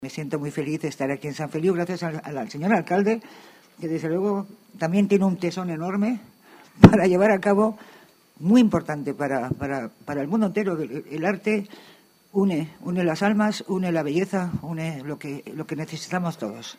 A la presentació hi va participar la baronessa Thyssen, Carmen Cervera, que va explicar la importància que té el projecte per ella.